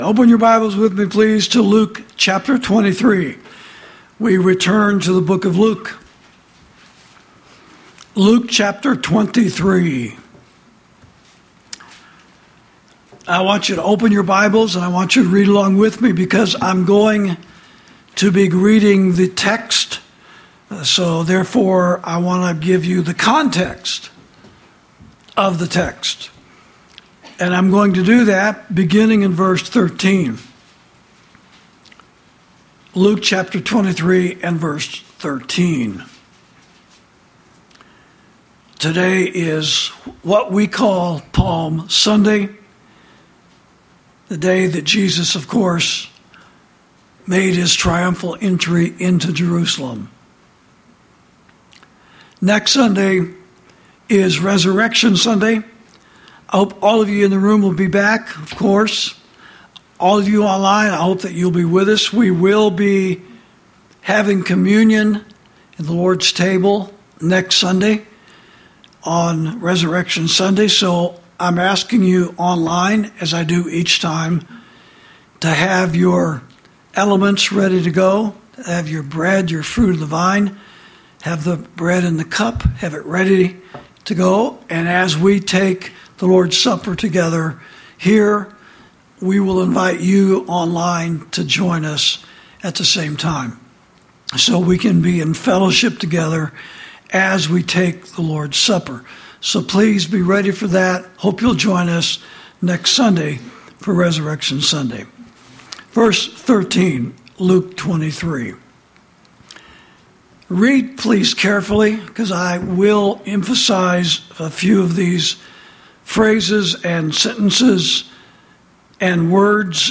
Sermons > The Three Crucifixions On Mount Calvary